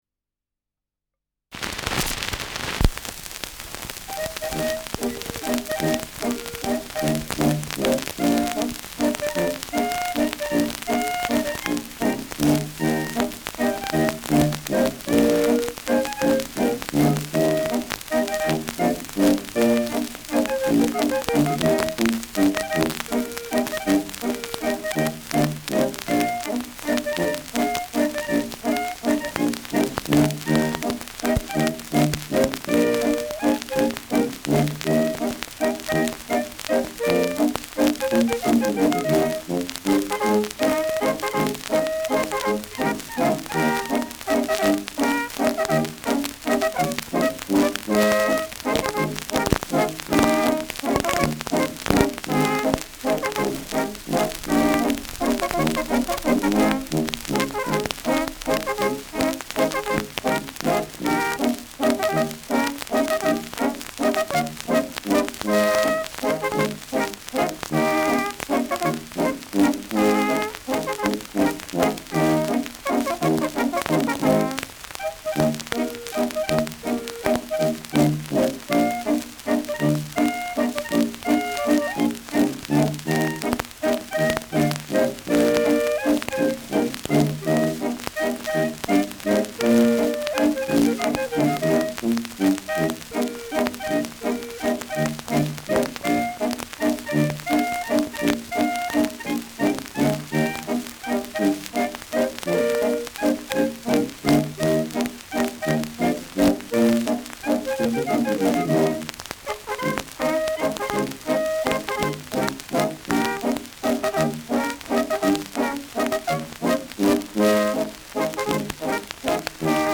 Schellackplatte
Starkes Grundknistern : Durchgehend leichtes bis starkes Knacken
Kapelle Bosl, Falkenstein (Interpretation)